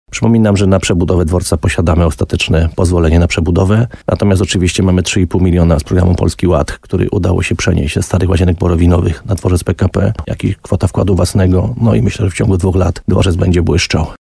Myślę, że w ciągu dwóch lat dworzec będzie błyszczał – mówi burmistrz, Piotr Ryba.